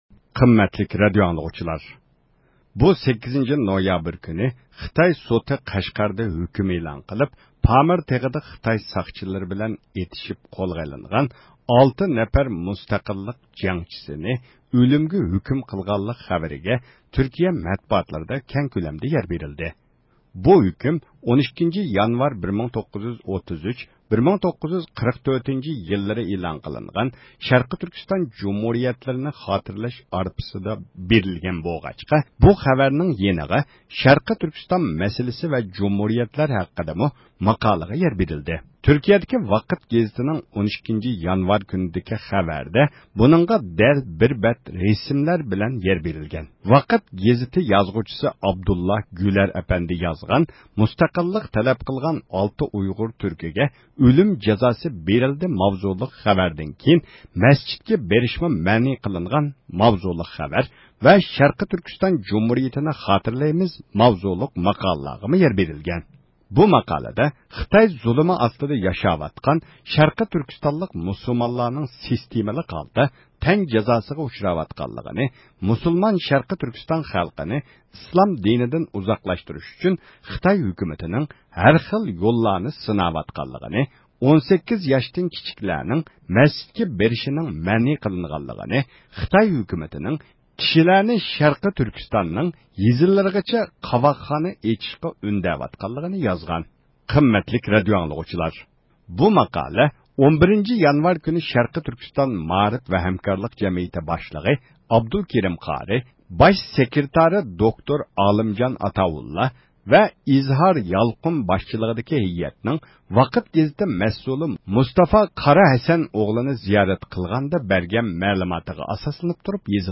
تېلېفون زىيارىتى ئېلىپ باردۇق